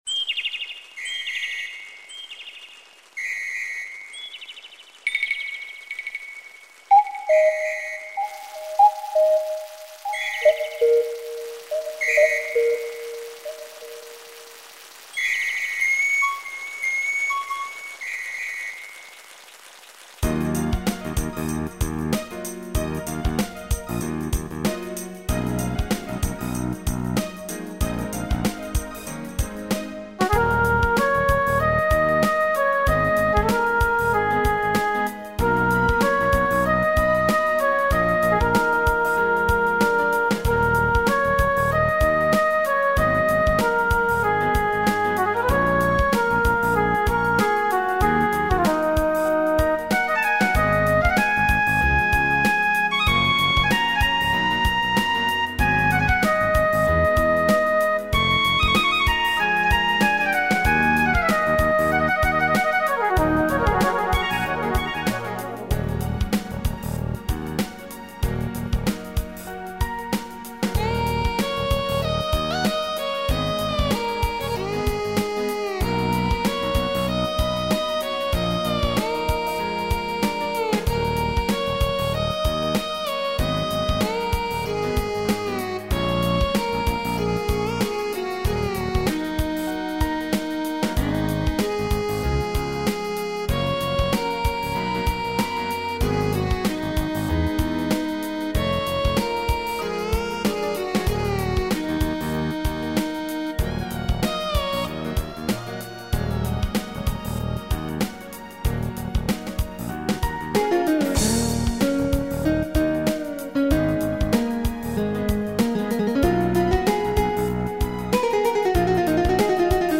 55 康定情歌--伴奏.MP3